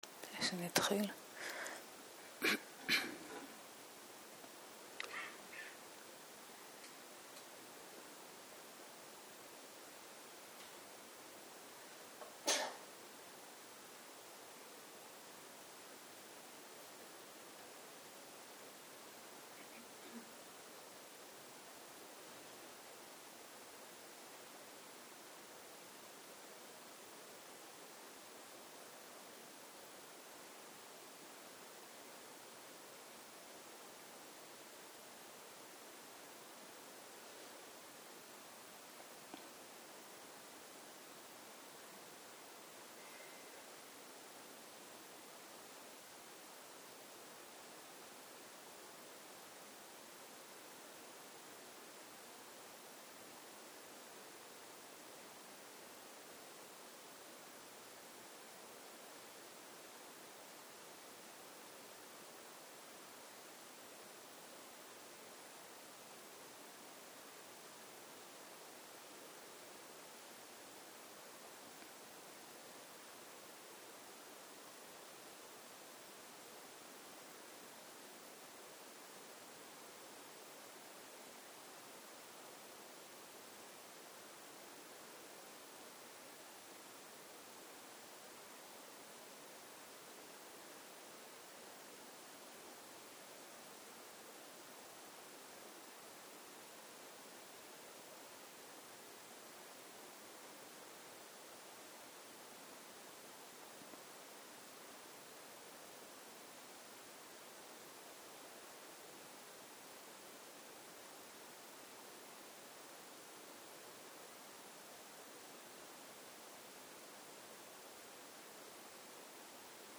ערב - שיחת דהרמה